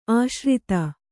♪ āśrita